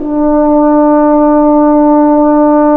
Index of /90_sSampleCDs/Roland L-CDX-03 Disk 2/BRS_French Horn/BRS_F.Horn 3 pp